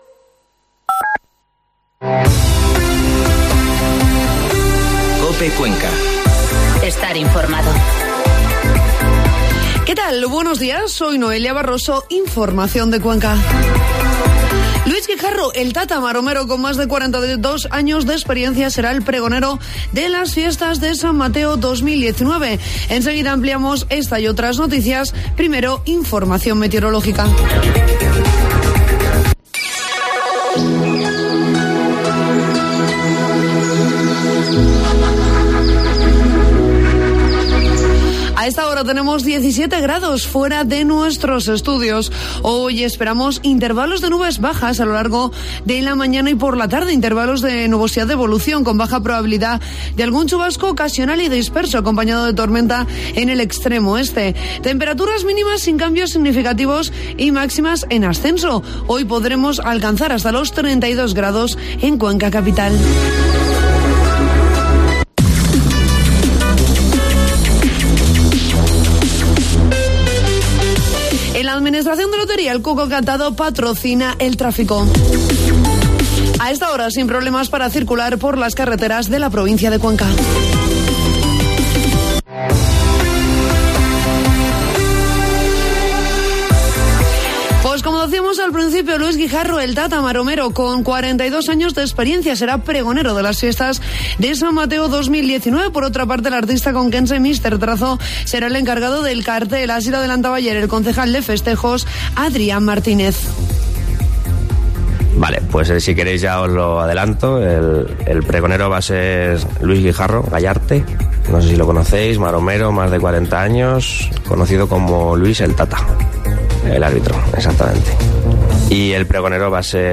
Informativo matinal COPE Cuenca 3 de septiembre